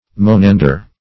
monander - definition of monander - synonyms, pronunciation, spelling from Free Dictionary Search Result for " monander" : The Collaborative International Dictionary of English v.0.48: Monander \Mo*nan"der\, n. (Bot.) One of the Monandria.